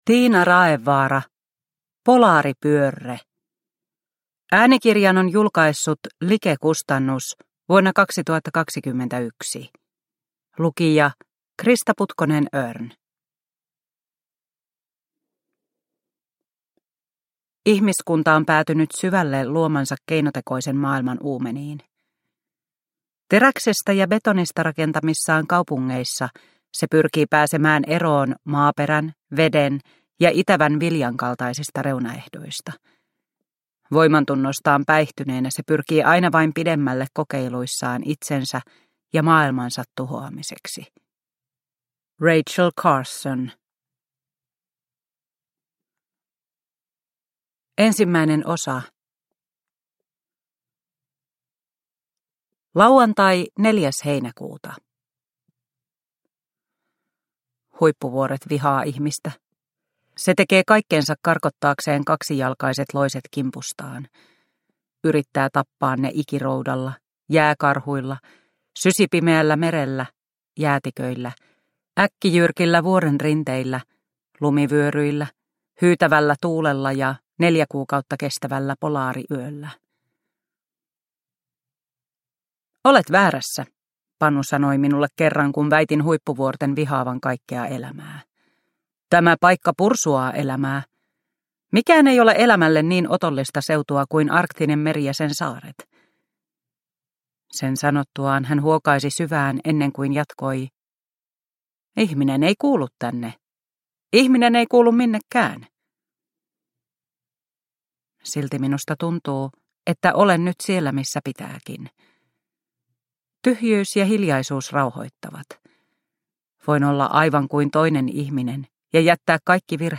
Polaaripyörre – Ljudbok – Laddas ner